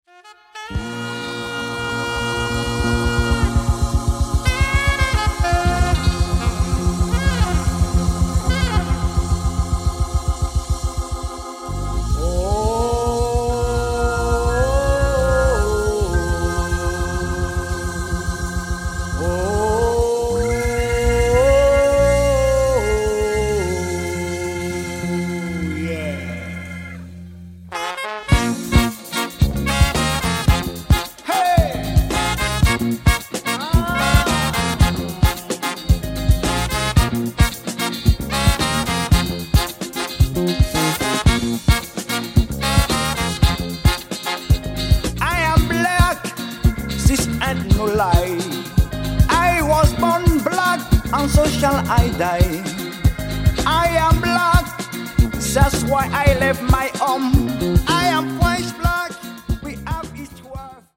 Heavy instrumental action